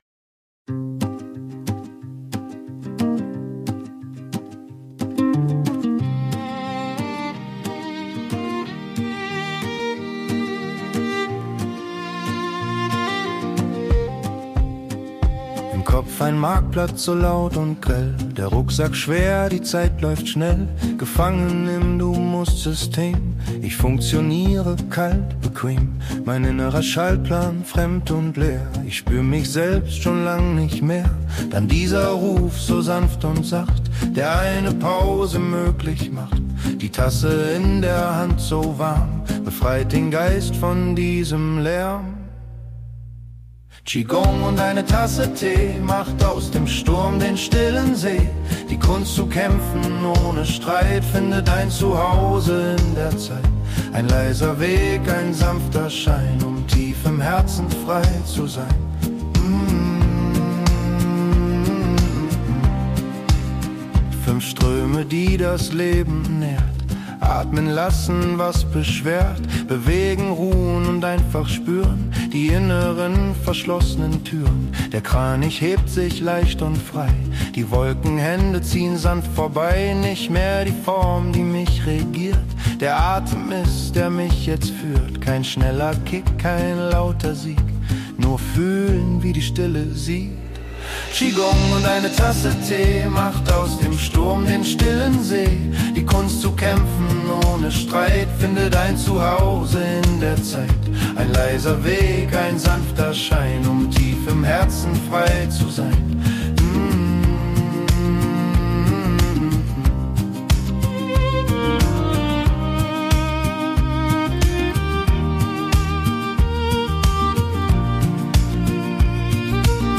Komposition mit Suno AI